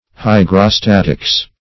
Search Result for " hygrostatics" : The Collaborative International Dictionary of English v.0.48: Hygrostatics \Hy`gro*stat"ics\, n. [Gr.